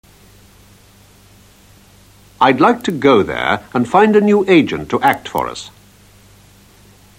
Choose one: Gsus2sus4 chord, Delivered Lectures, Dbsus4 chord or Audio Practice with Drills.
Audio Practice with Drills